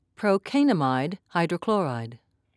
(proe-kane-a'mide)